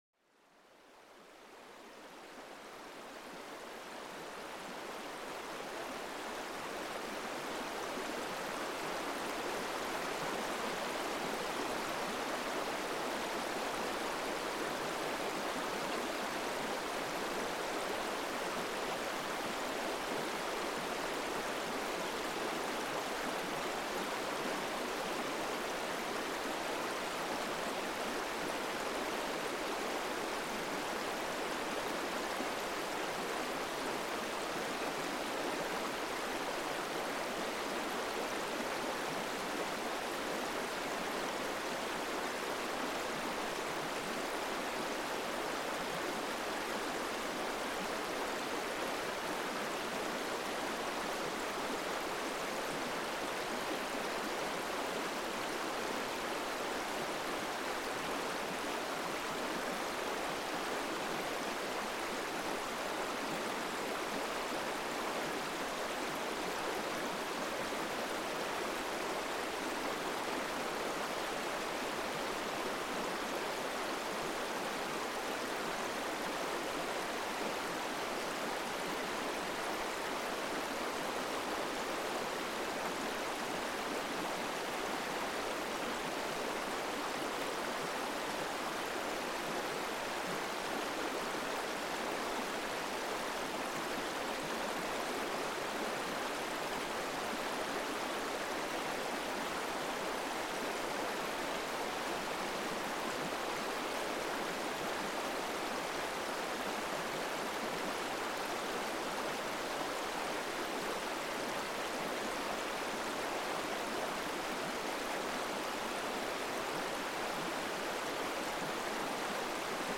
Aguas Tranquilas: Experimenta los sonidos relajantes de un río sereno